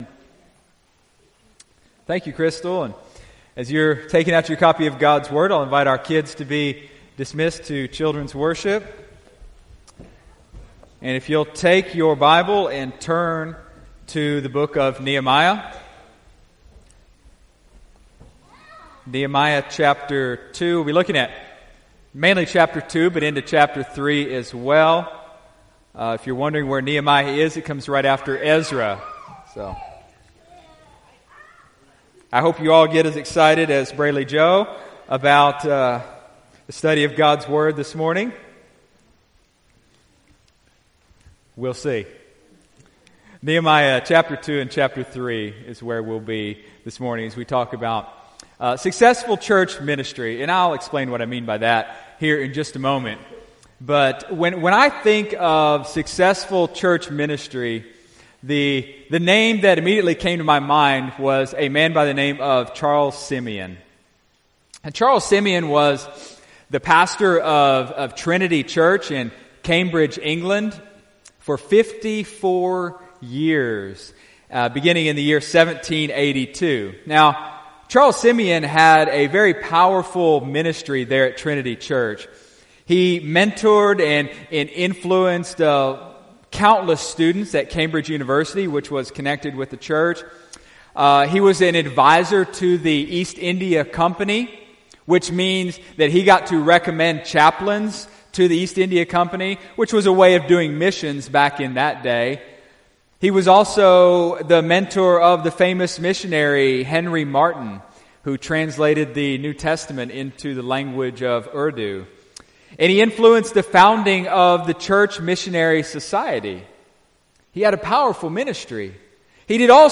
Successful Church Ministry | Dallasburg Baptist Church